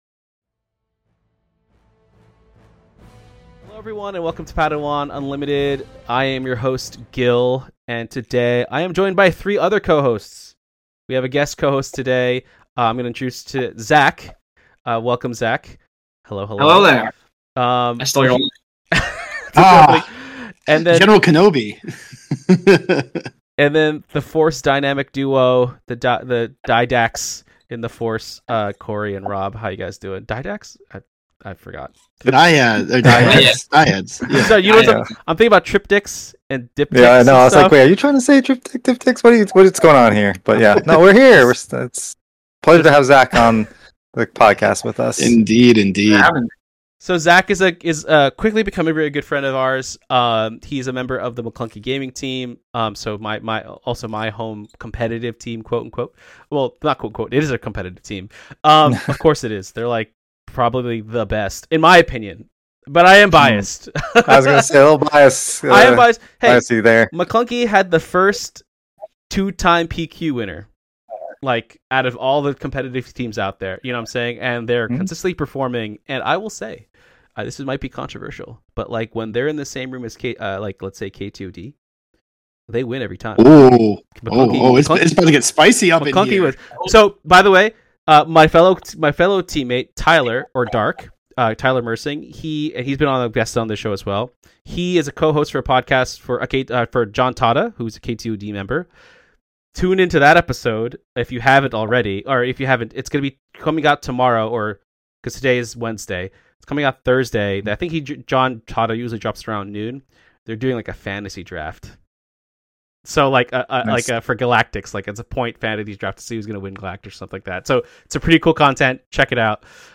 Always upbeat and positive, Padawan Unlimited is a a Star Wars Unlimited (SWU) Trading Card Game podcast dedicated to learning about and building the Star Wars Unlimited community, one episode, one post, one person at a time.